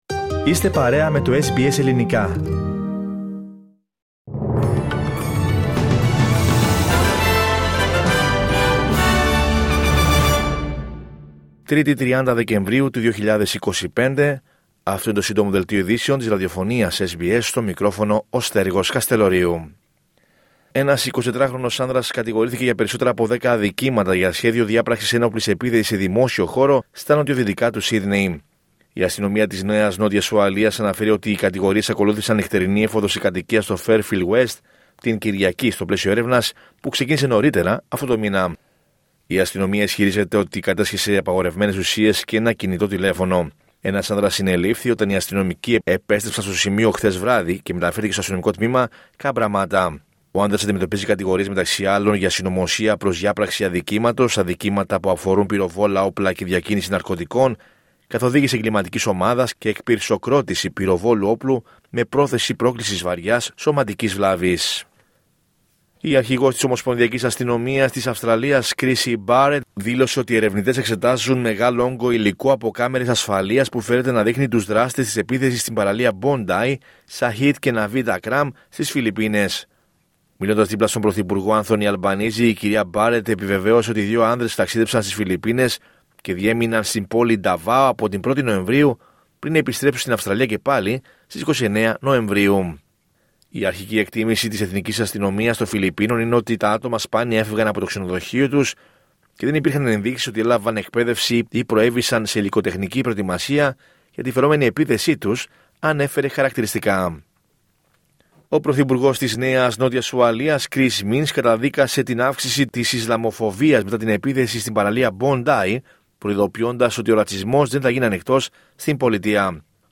H επικαιρότητα έως αυτή την ώρα στην Αυστραλία, την Ελλάδα, την Κύπρο και τον κόσμο στο Σύντομο Δελτίο Ειδήσεων της Τρίτης 30 Δεκεμβρίου 2025.